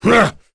Gau-Vox_Attack2.wav